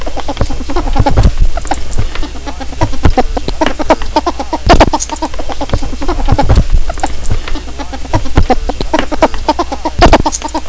My Ferrets (cute, very cute)
Chuckle! Chuckle! Dook! Dook! Furf! Chuckle!
chuckledook.wav